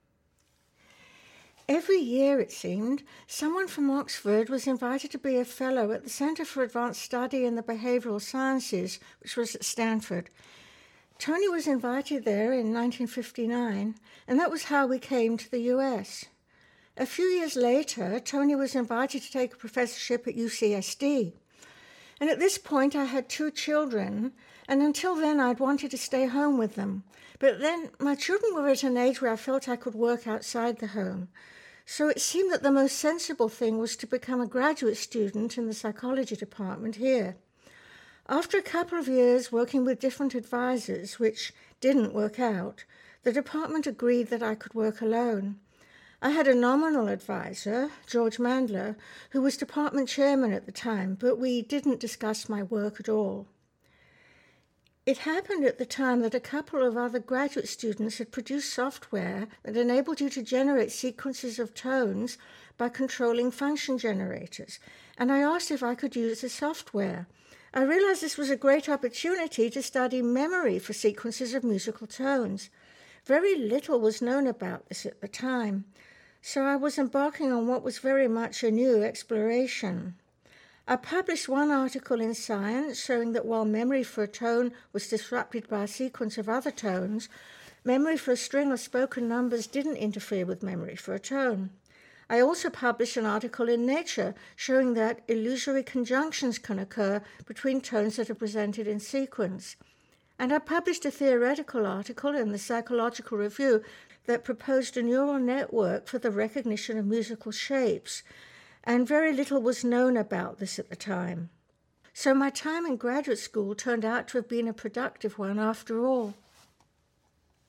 As Dr. Deutsch describes in this next narrative, there were many challenges to completing her doctoral thesis and earning her PhD.